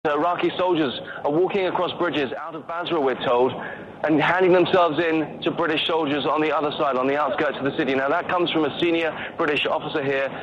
O sytuacji specjalnie dla Radia Zet, korespondent CNN (82Kb)